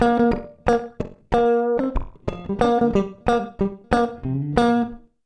92FUNKY  3.wav